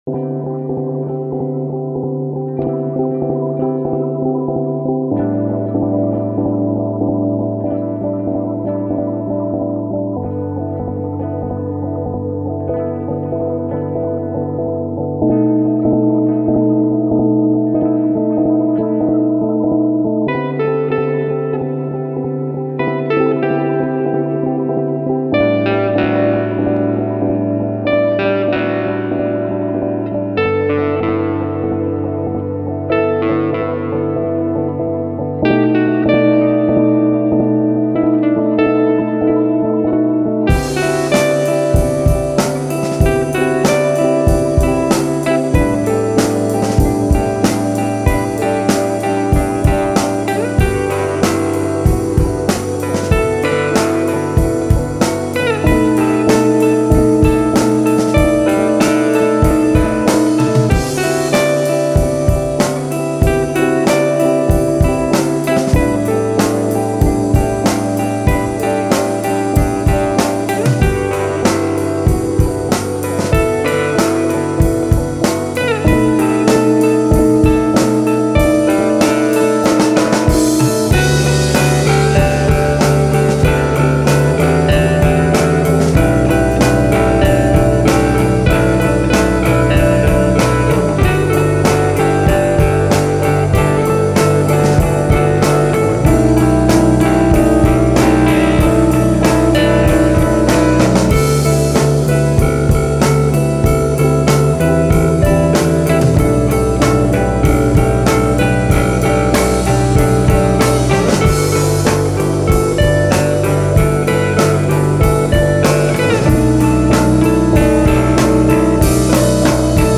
Solely instrumentals
drums and electronics